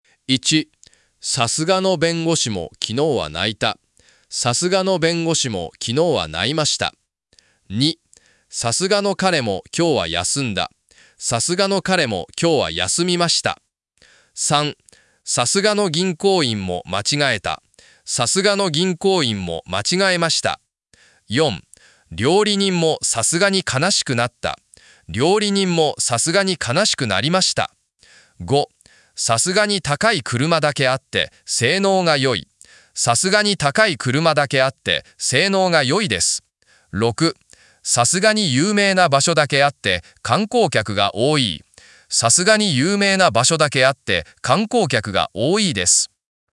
さすが【さす＼が /Sasuga/】 as expected / even for (someone as great as)